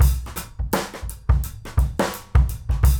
GROOVE 230ML.wav